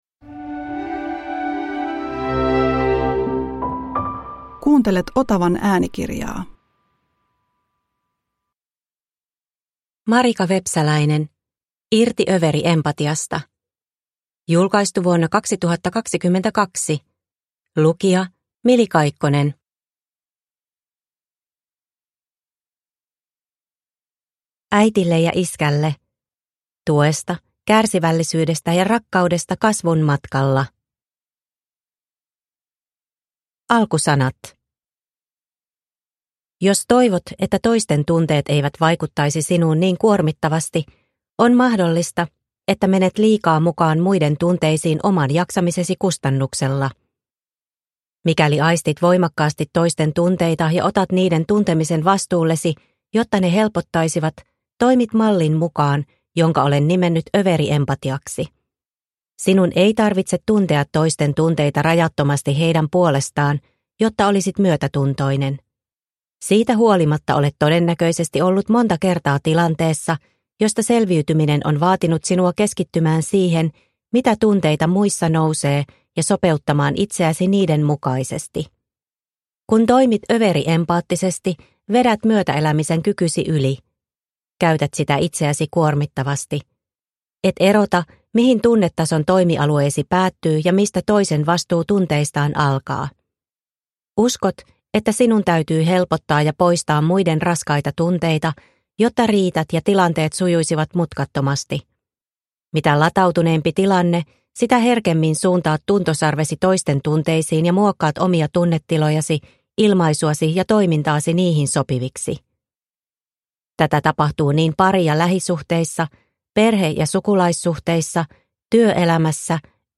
Irti överiempatiasta – Ljudbok – Laddas ner